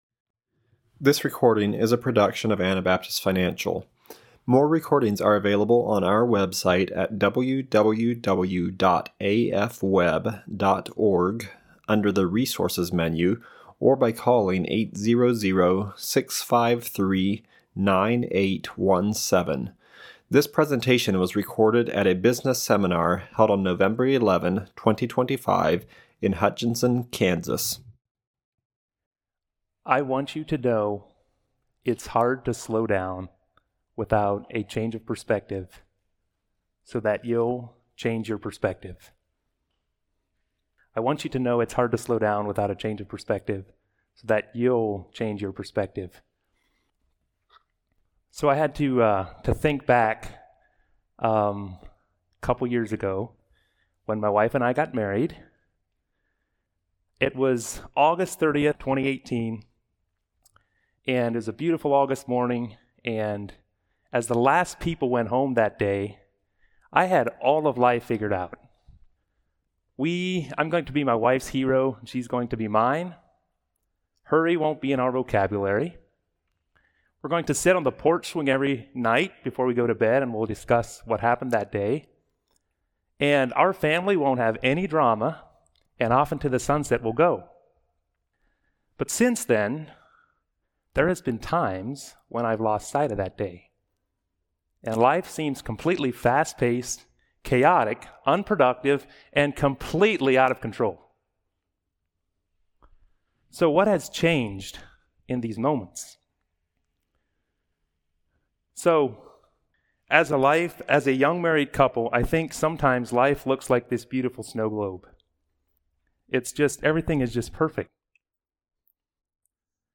Kansas Business Seminar 2025